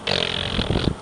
Tongue Sound Effect
Download a high-quality tongue sound effect.
tongue-2.mp3